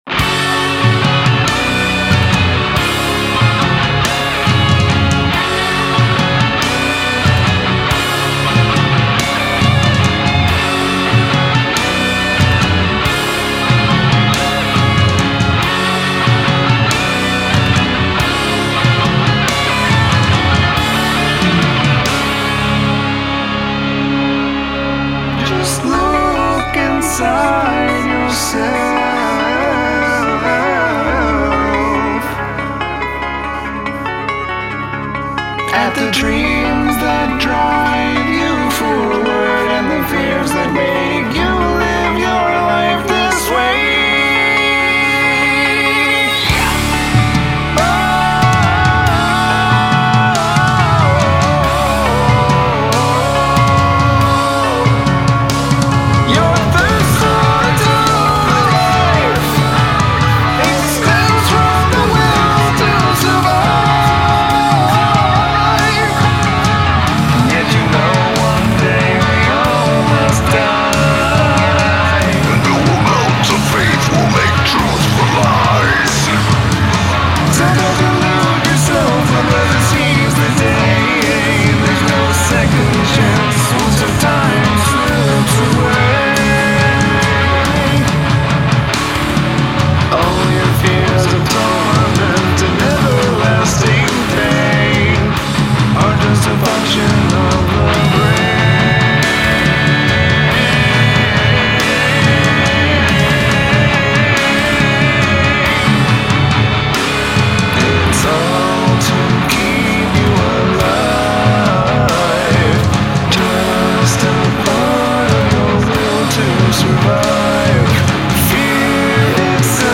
Guest Vocals